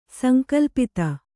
♪ sankalpita